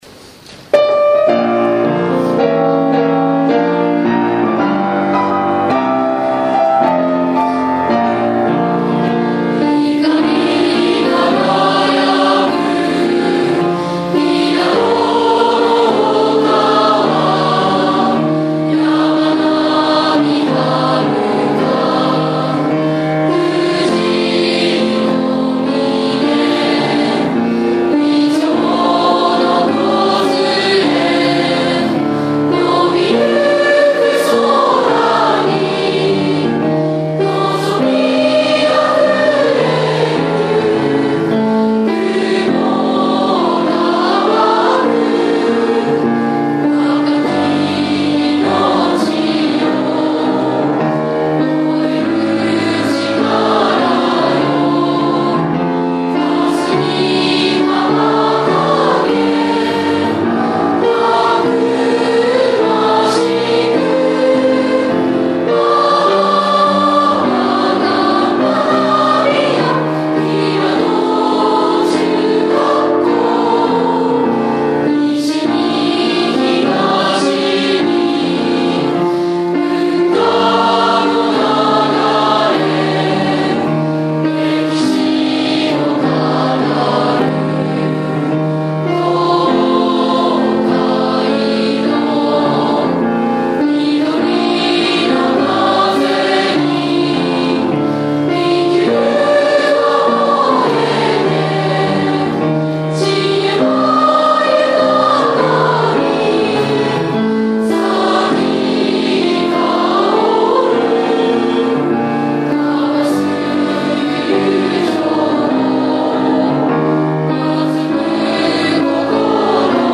★校歌範唱★［mp3］ 　　★校歌範唱★ [wma]